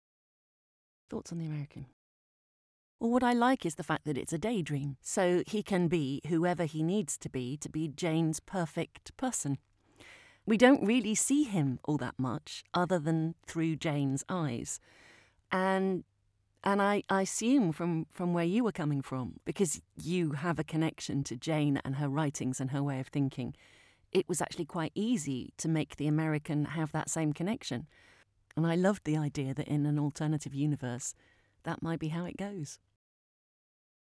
I hope you dug listening to the interview as much as I did and will take a listen to the audiobook.